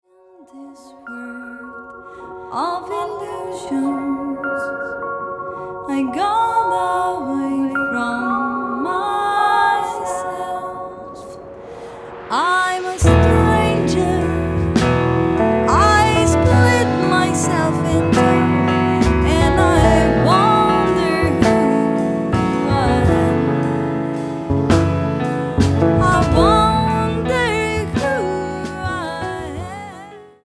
vocals
guitar
piano/Fender Rhodes
bass
drums